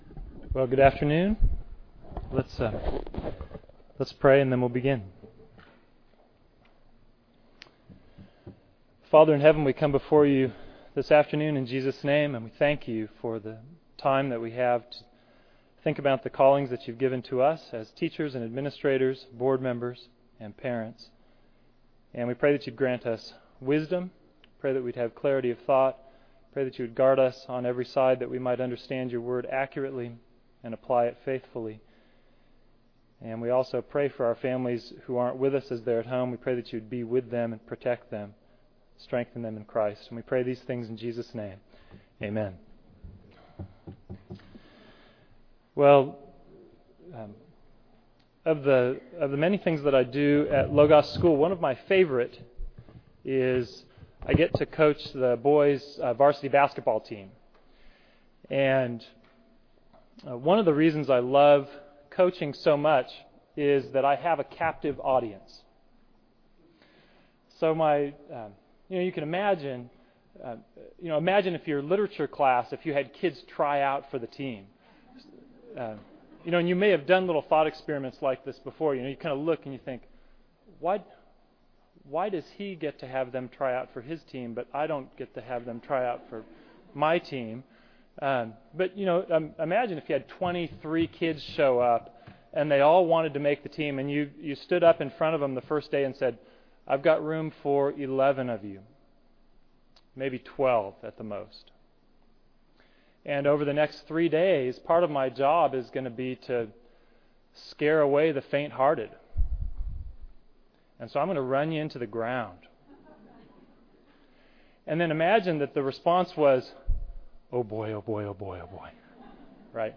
2009 Plenary Talk | 1:02:48 | All Grade Levels, Virtue, Character, Discipline